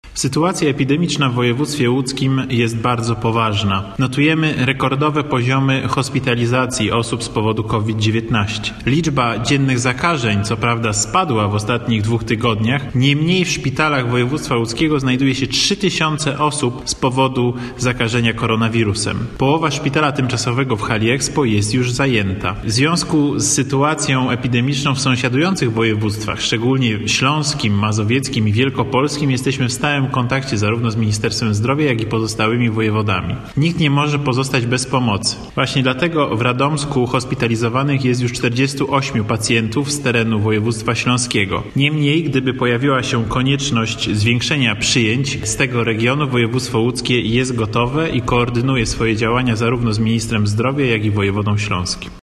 Sytuacja epidemiczna w województwie łódzkim jest bardzo poważna – mówi Tobiasz Bocheński, wojewoda łódzki.